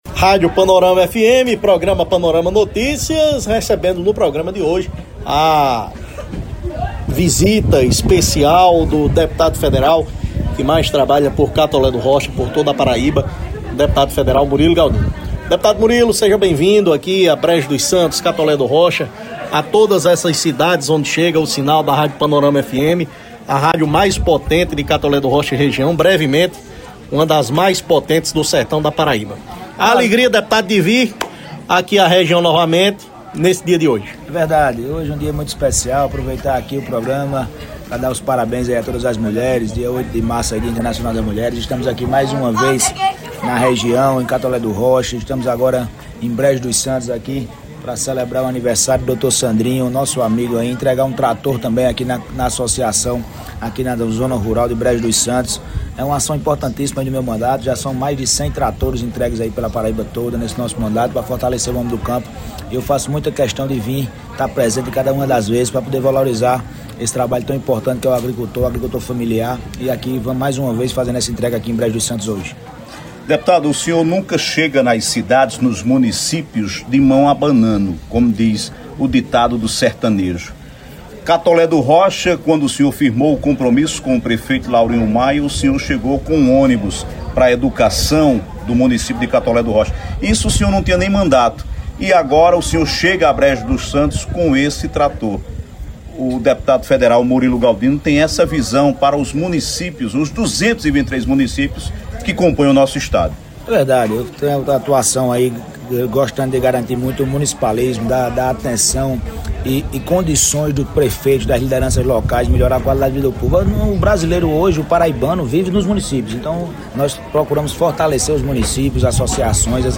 Durante entrevista concedida ao programa Panorama Notícias, da Rádio Panorama FM 96,7 que foi ao ar na segunda-feira (09), o deputado federal Murilo Galdino destacou ações do seu mandato em benefício de Catolé do Rocha e de diversos municípios do Sertão da Paraíba, além de comentar o cenário político estadual e as perspectivas para as eleições de 2026.
01-Dep.-Federal-Murilo-Galdino-Entrevista.mp3